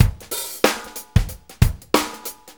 Shuffle Loop 28-02.wav